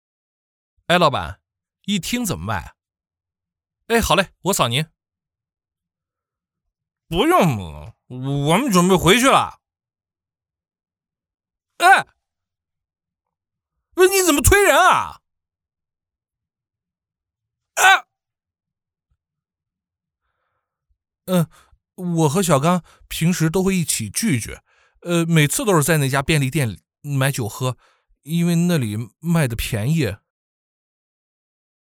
【角色】少年男
【角色】少年男.mp3